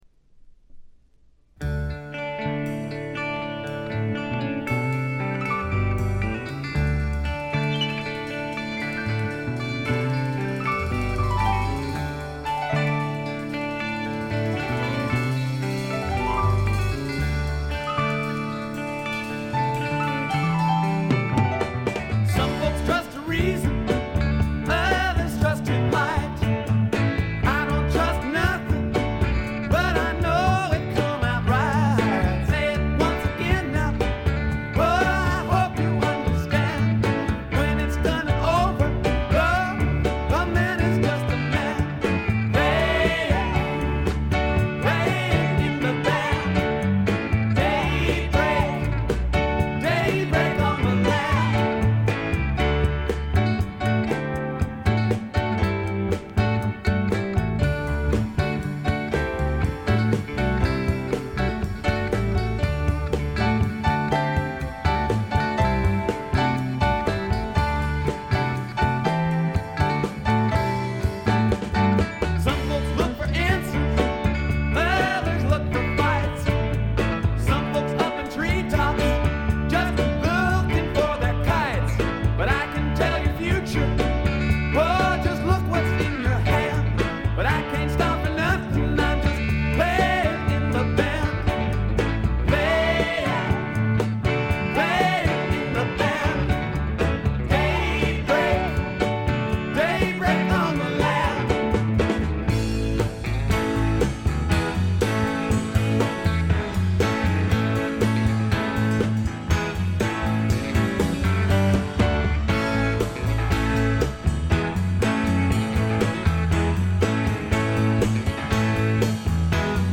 部分試聴ですが、微細なチリプチ程度でほとんどノイズ感無し。
試聴曲は現品からの取り込み音源です。